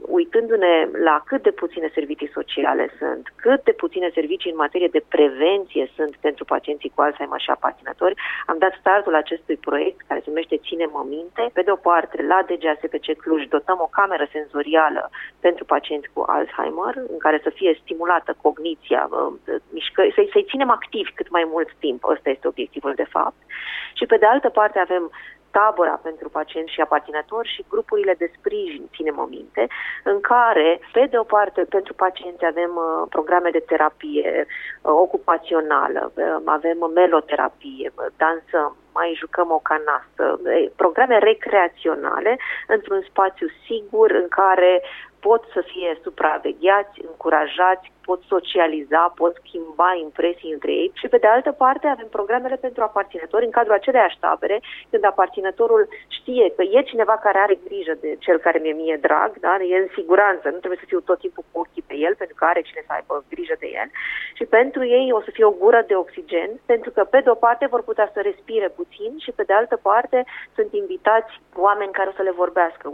Invitată astăzi la Radio Cluj, Melania Medeleanu, fondatoarea Asociației Zi de Bine: